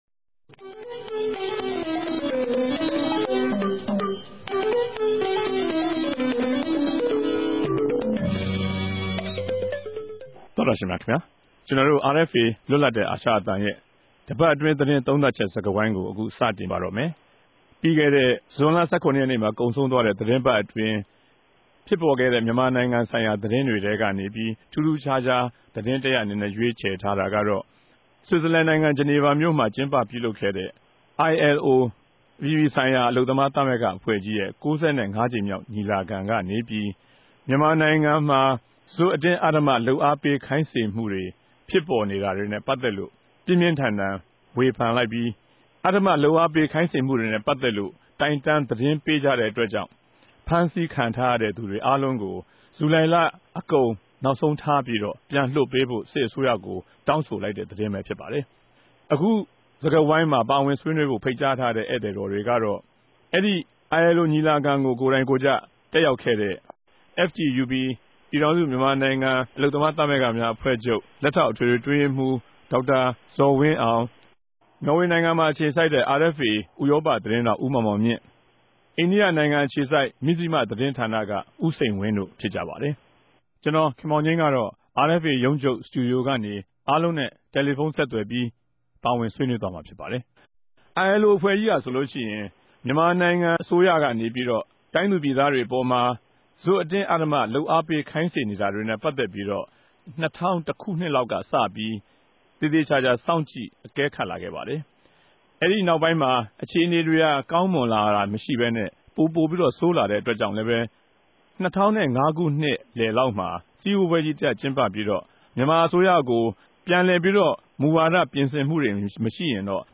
တပတ်အတြင်းသတင်းသုံးသပ်ခဵက် စကားဝိုင်း (၂၀၀၆ ဇြန်လ ၁၇ရက်)